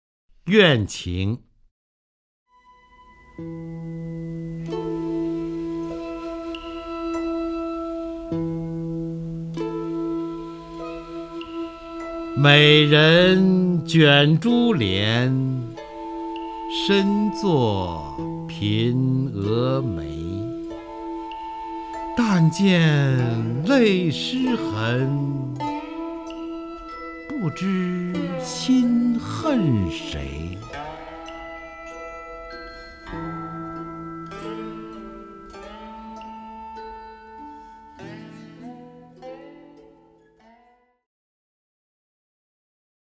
方明朗诵：《怨情》(（唐）李白) （唐）李白 名家朗诵欣赏方明 语文PLUS